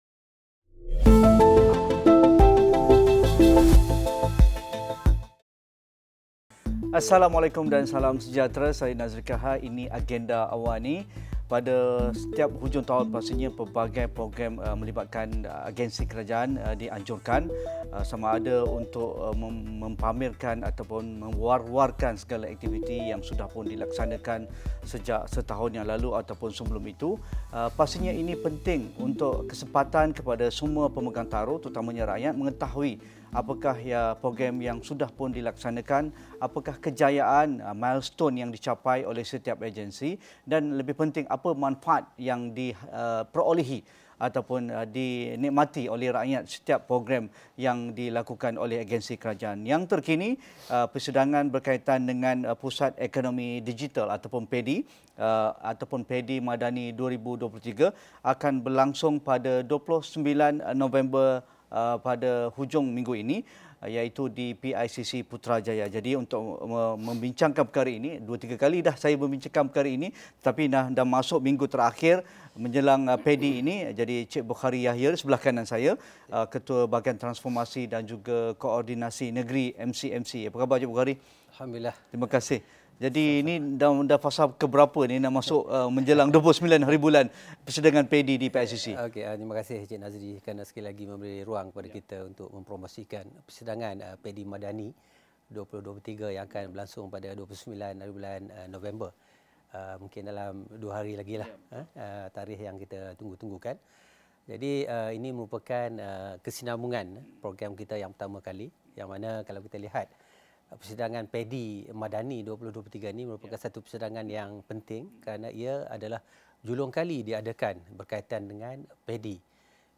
Buat julung kalinya, Persidangan Pusat Ekonomi Digital (PEDi) MADANI 2023 dianjurkan pada 29 November untuk memperkasa komuniti setempat menerusi digitalisasi dan pemangkin bagi ekosistem digital agar dapat memenuhi keperluan masa depan yang inovatif. Diskusi 9 malam